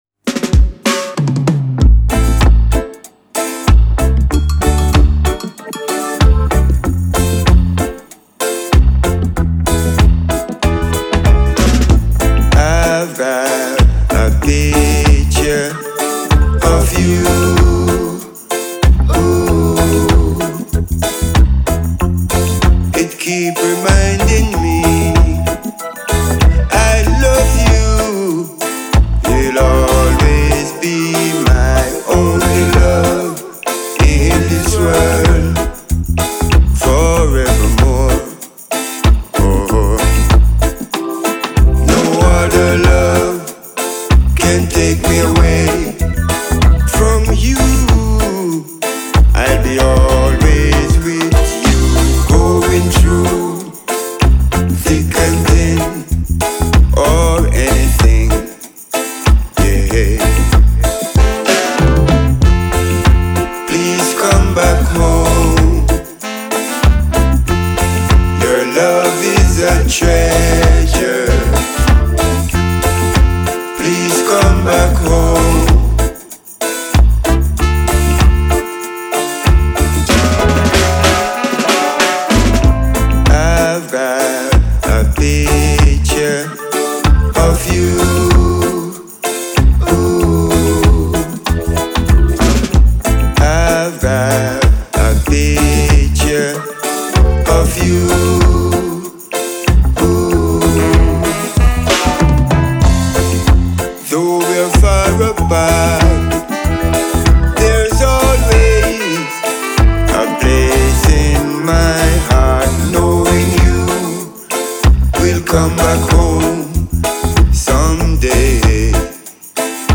reggae collective
composed of 9 passionate musicians.